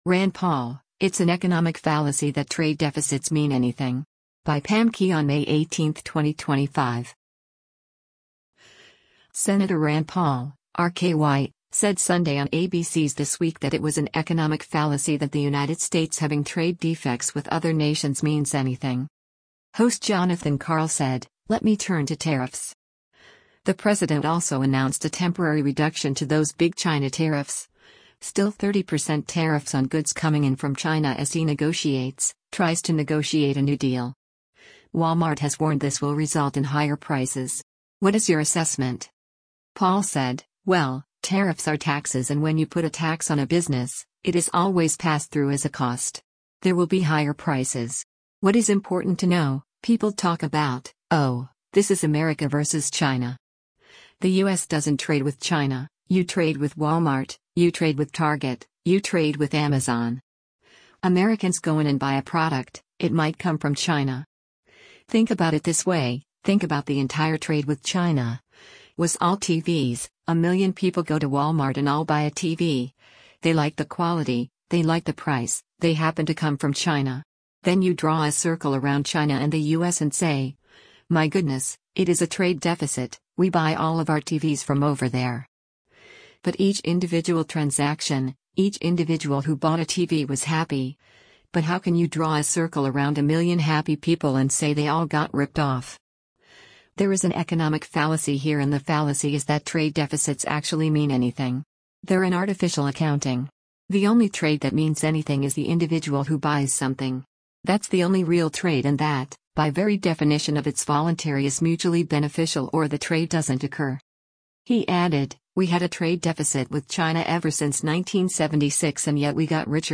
Senator Rand Paul (R-KY) said Sunday on ABC’s This Week” that it was an “economic fallacy” that the United States having trade defects with other nations means anything.